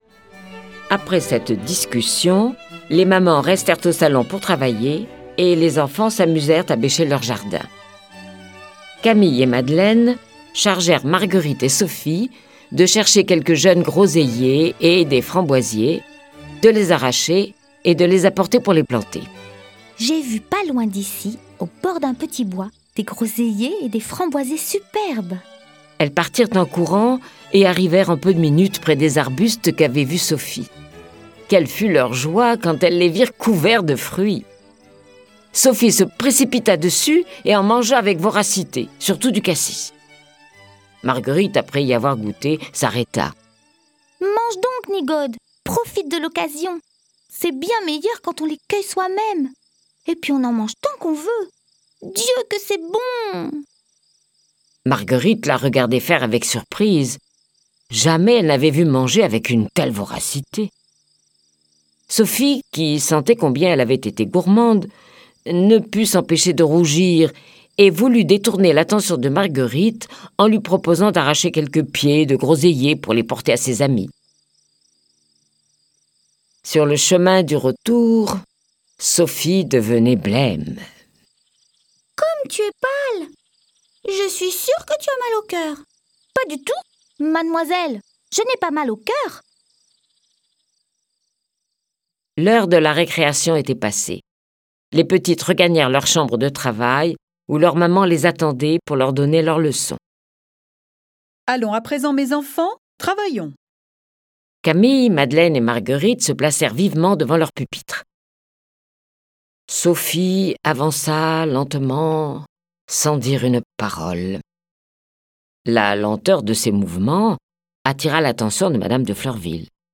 Diffusion distribution ebook et livre audio - Catalogue livres numériques
Cette version sonore est animée par neuf voix et accompagnée de plus de trente morceaux de musique classique.